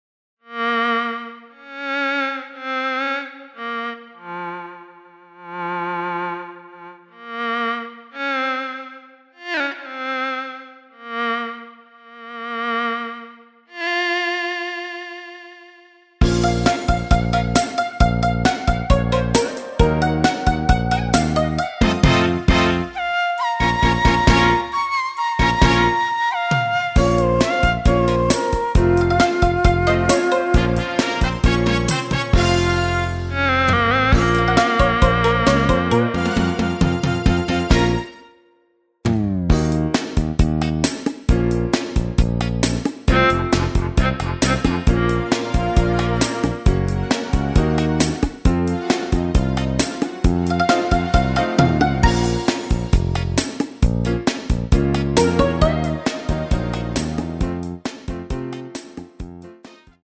Bbm
앞부분30초, 뒷부분30초씩 편집해서 올려 드리고 있습니다.
중간에 음이 끈어지고 다시 나오는 이유는